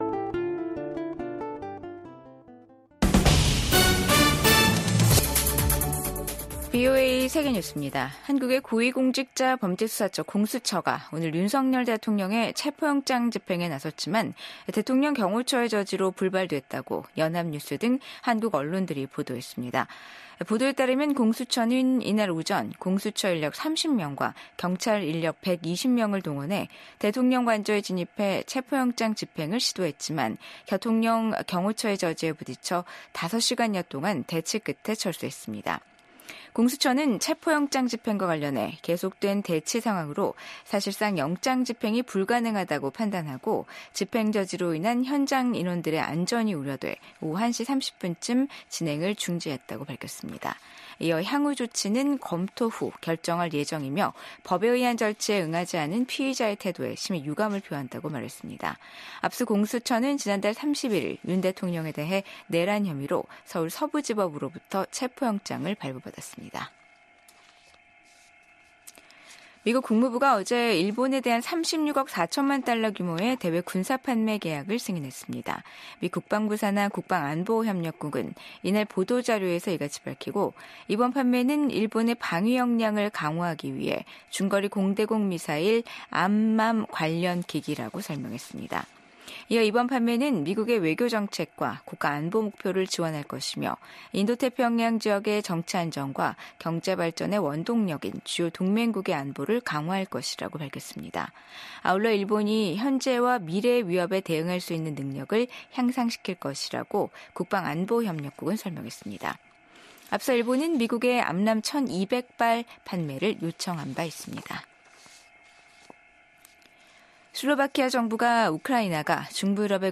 VOA 한국어 간판 뉴스 프로그램 '뉴스 투데이', 2025년 1월 3일 3부 방송입니다. 한국에서 현직 대통령에 대한 사법당국의 체포 시도라는 사상 초유의 일이 벌어졌습니다. 최상목 한국 대통령 권한대행 부총리 겸 기획재정부 장관은 오늘(3일) 오후 정부서울청사에서 필립 골드버그 주한 미국대사와 제이비어 브런슨 주한미군사령관을 공동 접견했습니다. 2025년 새해가 밝았지만, 북한군 병사들이 타국의 전쟁터에서 희생되고 있다는 소식이 이어지고 있습니다.